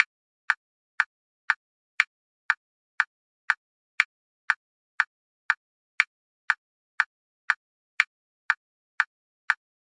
古钟
描述：我的祖父钟。
Tag: 祖父时钟 滴答 时间 井字 滴答滴答 时钟